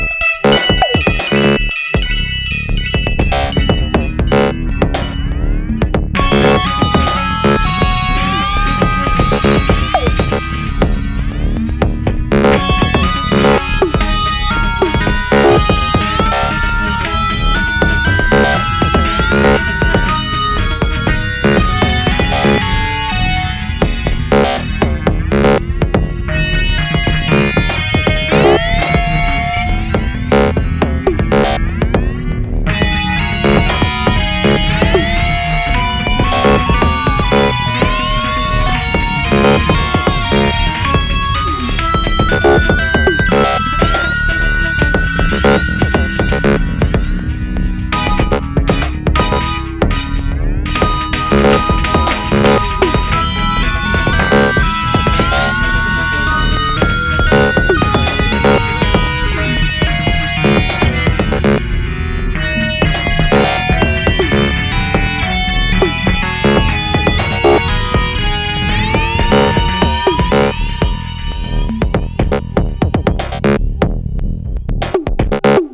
BGM EDM
激しい